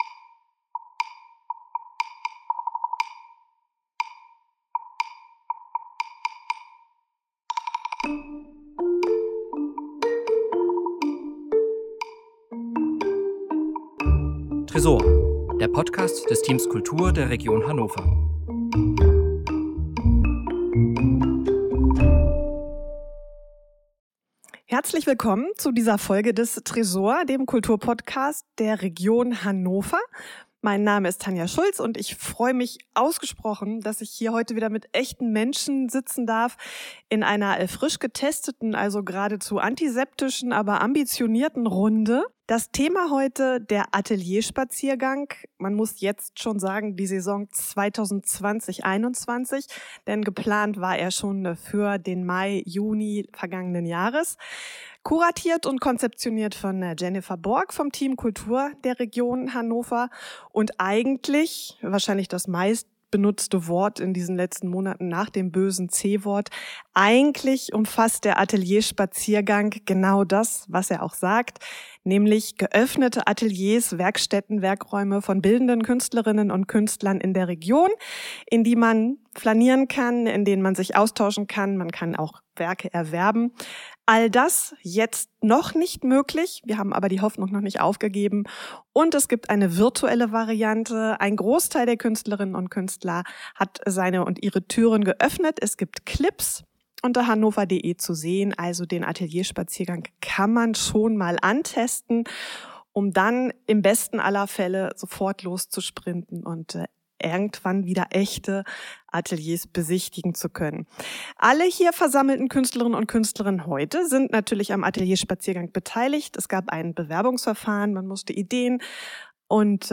Ort des Hör-Geschehens: der Tresorraum im Keller von Schloss Landestrost